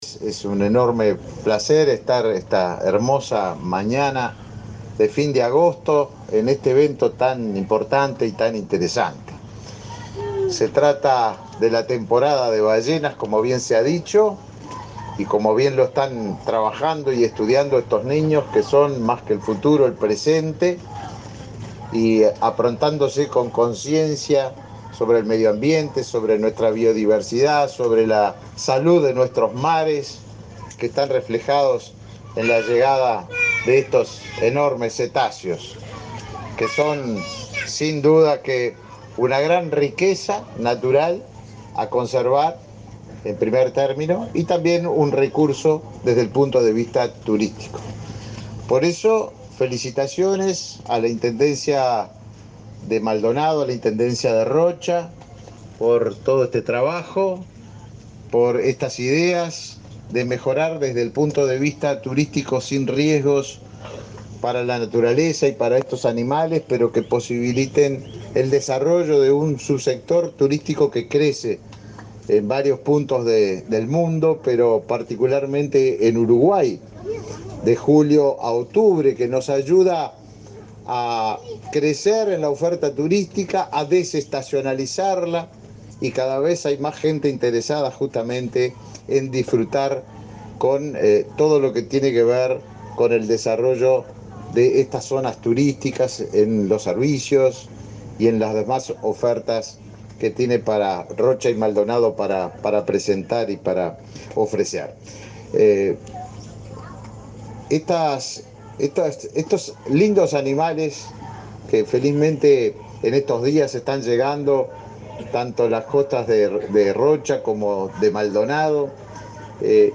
Palabras del ministro de Turismo, Tabaré Viera
El ministro de Turismo, Tabaré Viera, participó del lanzamiento de la temporada de avistamiento de ballenas en el balneario La Paloma, departamento de